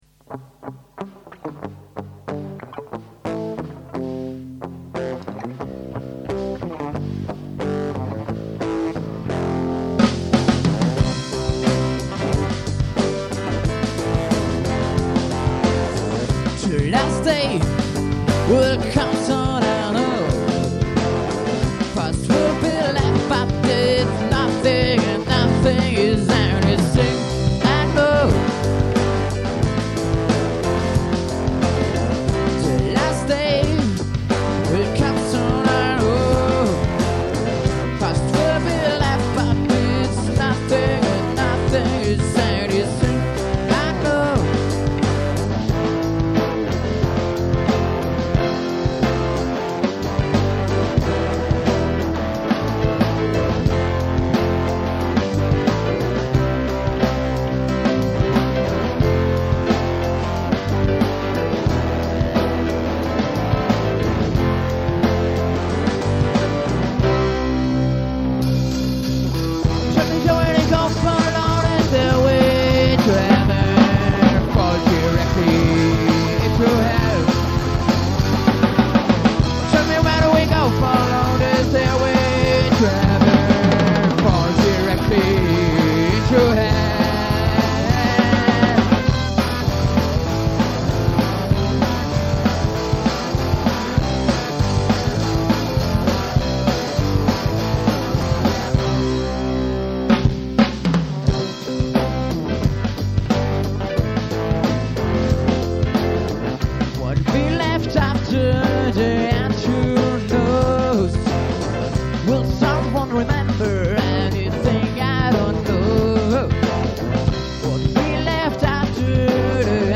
probenmitschnitt